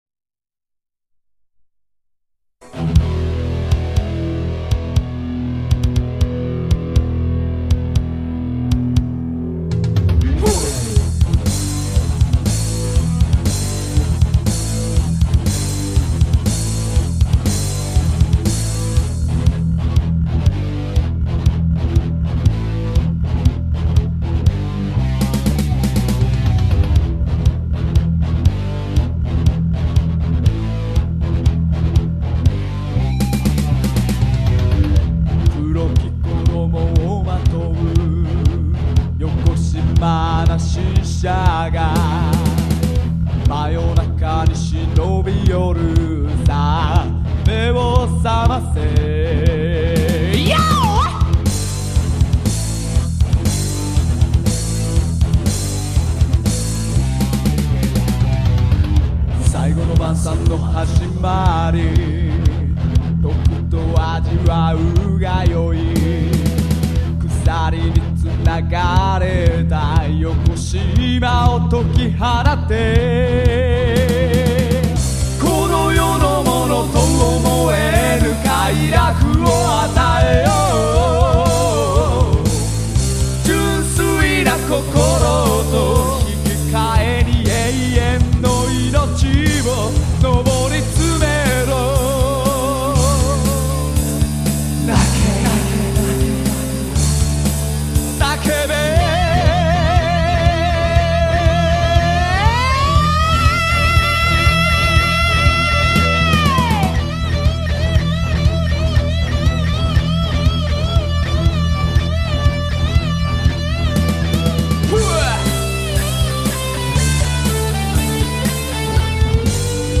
『cry out』の再RECです。
俺のギターなどどうでもイイので